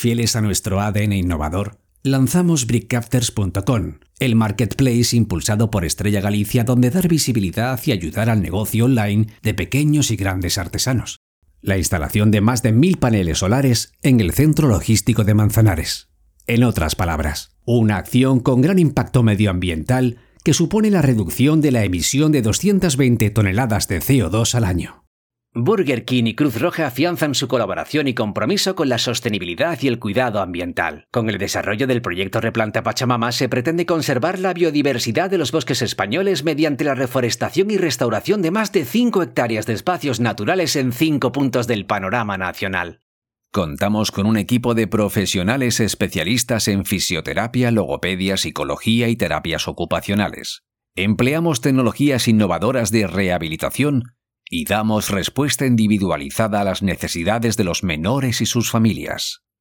Género: Masculino
Corporativo